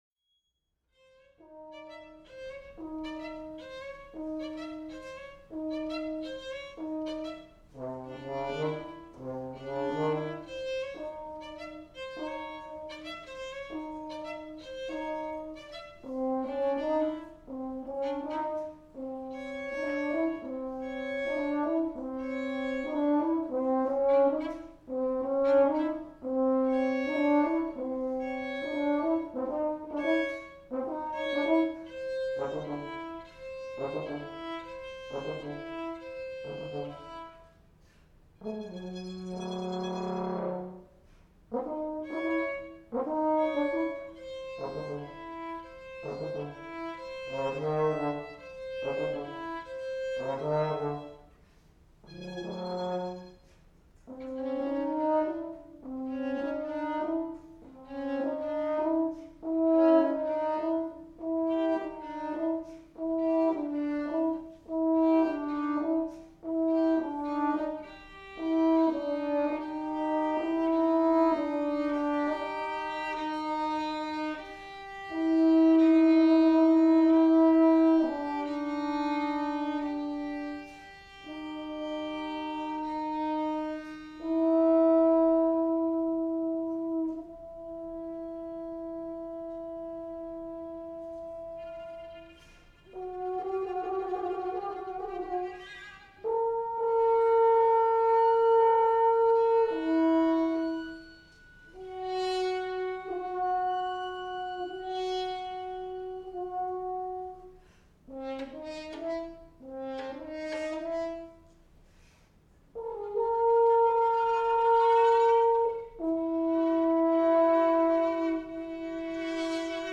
Instrumentation: horn and violin